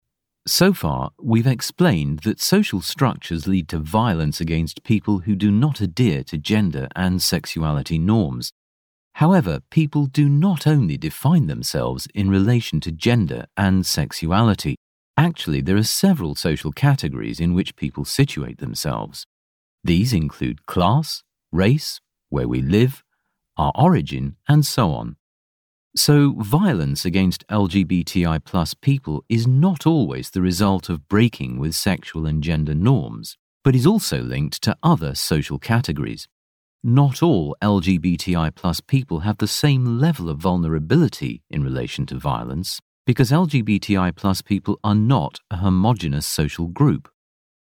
Anglais (Britannique)
Profonde, Naturelle, Chaude
Vidéo explicative
Vocal range from 30 to Senior.